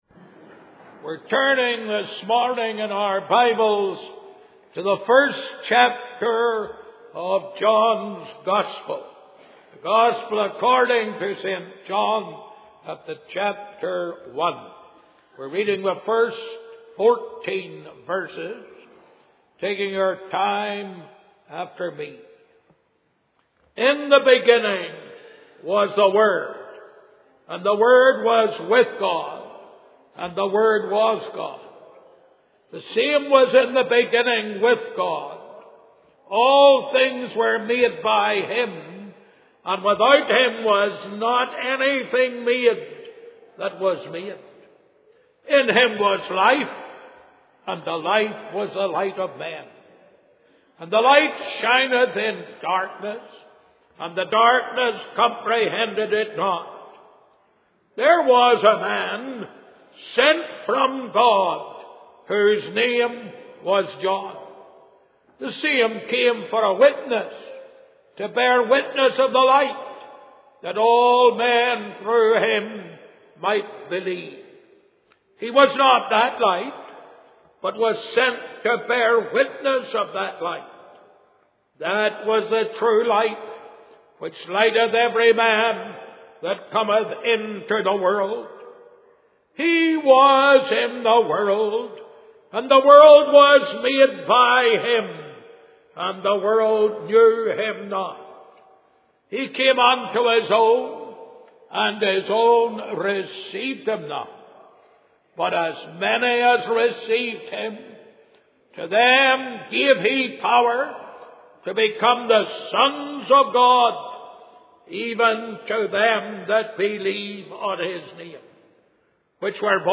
In this sermon, the speaker emphasizes the importance of paying attention to and retaining the word of God. He compares the word of God to a seed that needs to be inspected and allowed to take root in our hearts.